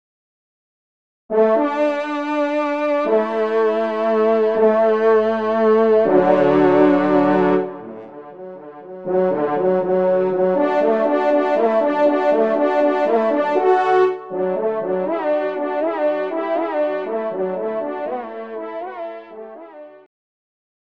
Genre : Fantaisie Liturgique pour quatre trompes
Pupitre 2°Trompe